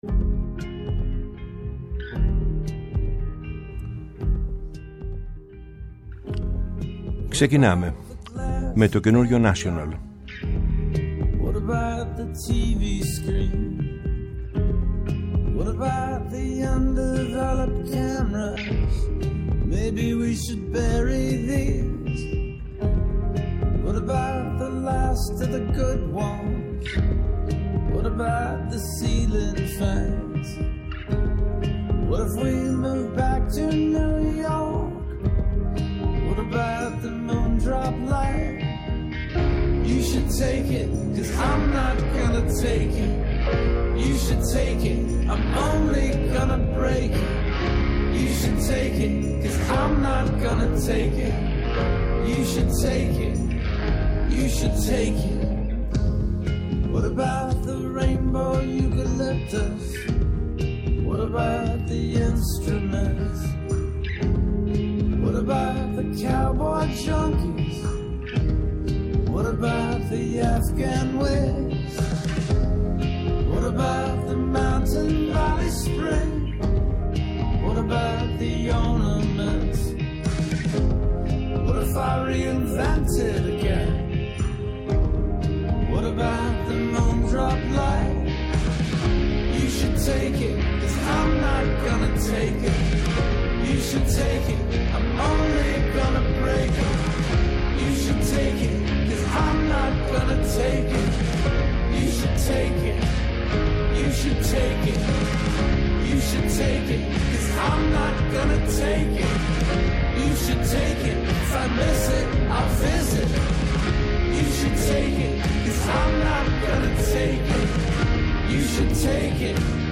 Η μακροβιότερη εκπομπή στο Ελληνικό Ραδιόφωνο!
Από το 1975 ως τον Ιούνιο 2013 και από το 2017 ως σήμερα, ο Γιάννης Πετρίδης βρίσκεται στις συχνότητες της Ελληνικής Ραδιοφωνίας, καθημερινά “Από τις 4 στις 5” το απόγευμα στο Πρώτο Πρόγραμμα